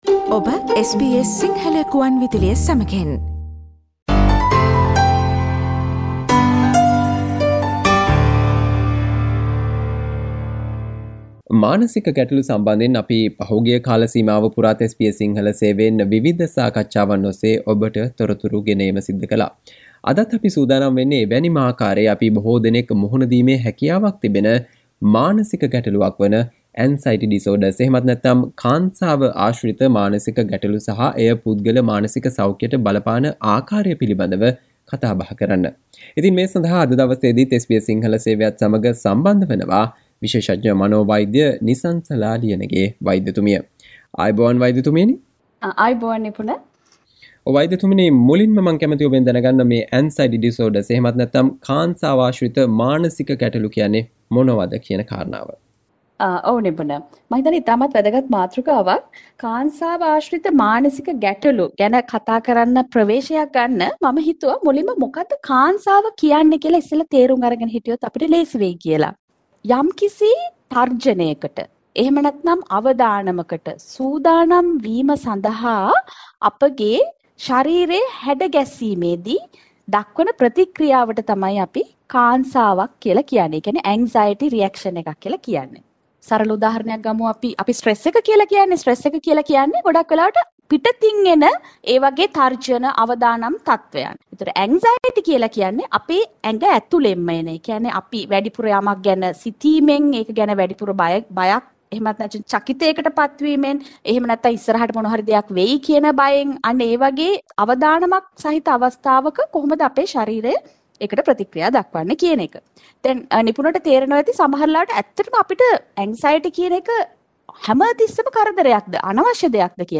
මානසික ගැටලුවක් වන Anxiety Disorders හෙවත් "කාන්සාව" ආශ්‍රිත මානසික ගටලු සහ එය පුද්ගල මානසික සෞඛ්‍යයටබලපාන අකාරය පිළිබඳව SBS සිංහල සේවය සිදු කල සාකච්චාවට සවන් දෙන්න